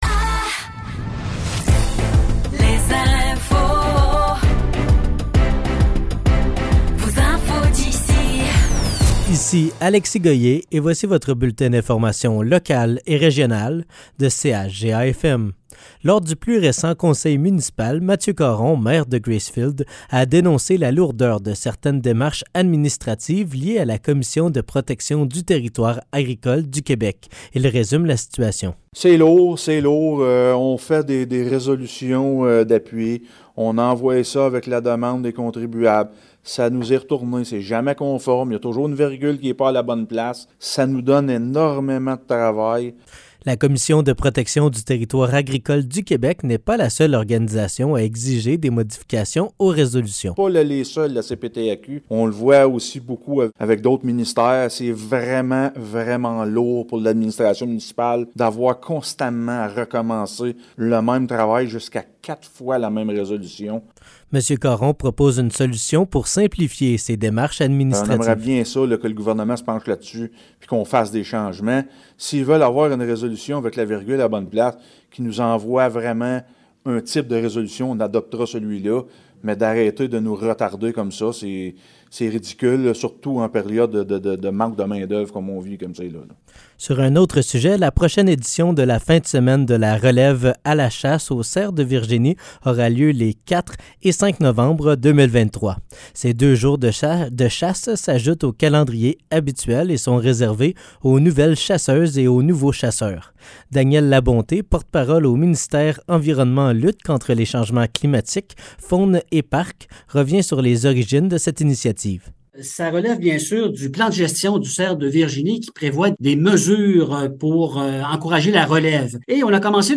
Nouvelles locales - 12 octobre 2023 - 15 h